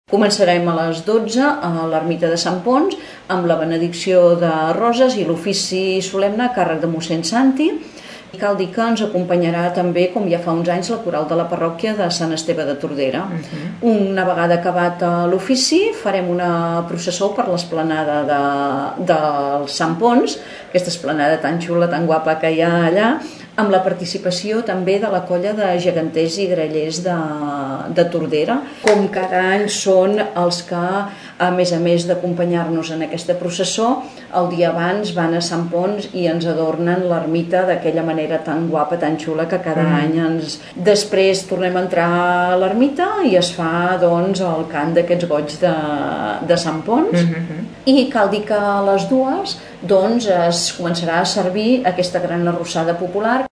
En dona detalls la regidora de festes, Maria Àngels Cayró.